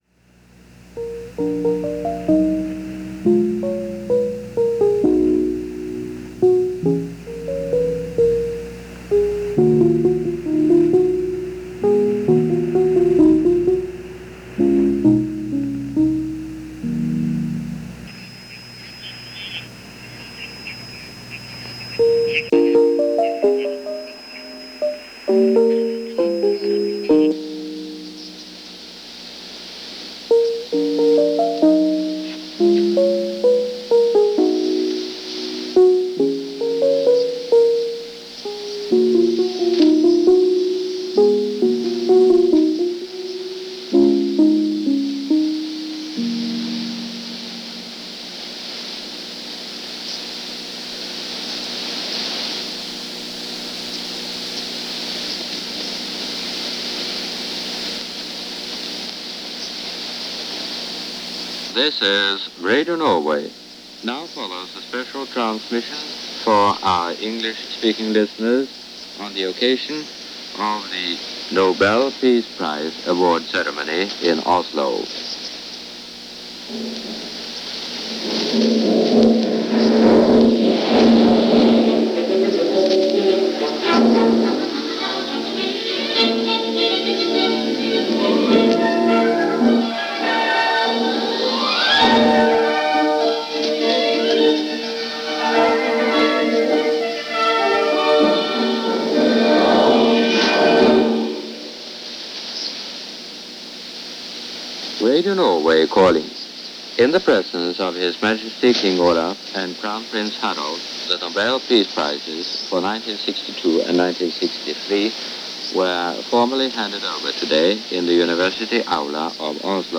Linus Pauling Accepts The 1962 Nobel Peace Prize - December 10, 1963 - Past Daily Reference Room - Radio Norway English Service Broadcast.
Linus Pauling Accepting the Nobel Peace Prize in Oslo.
Radio-Norway-Nobel-Prize.mp3